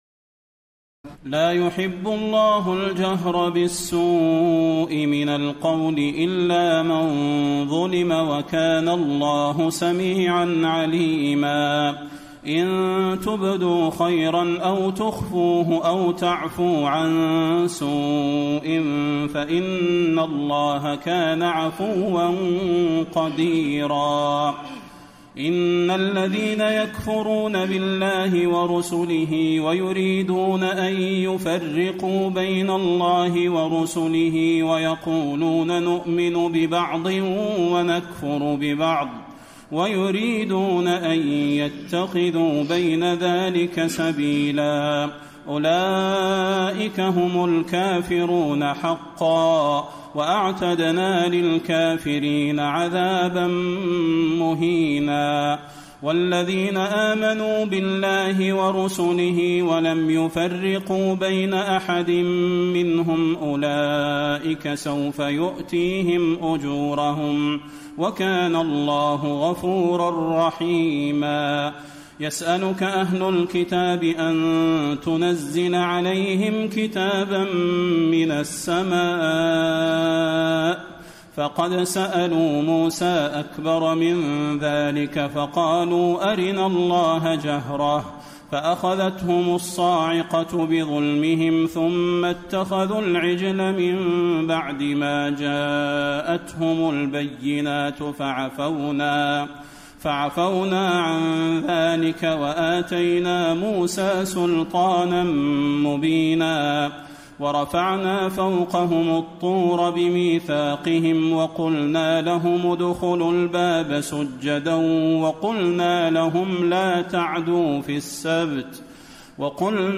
تراويح الليلة السادسة رمضان 1434هـ من سورتي النساء (148-176) و المائدة (1-26) Taraweeh 6 st night Ramadan 1434H from Surah An-Nisaa and AlMa'idah > تراويح الحرم النبوي عام 1434 🕌 > التراويح - تلاوات الحرمين